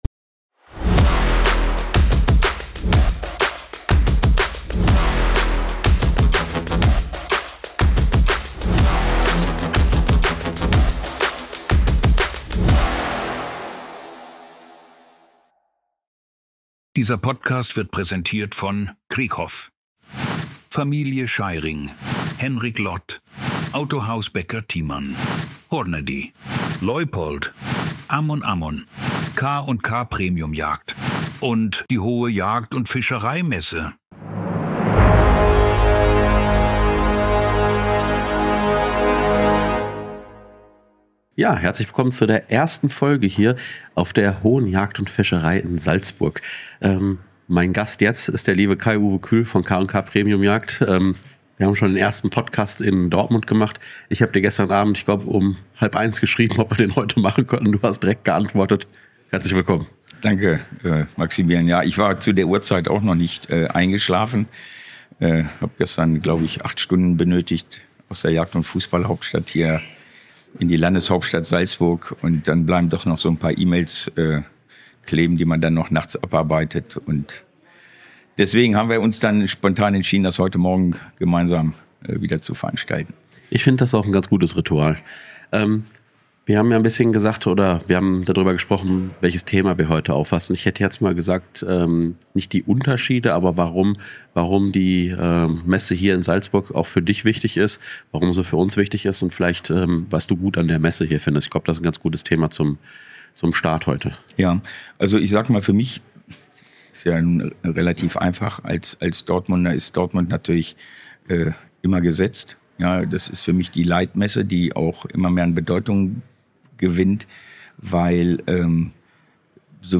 FSW Podcast Folge 131: Live von der Hohen Jagd in Salzburg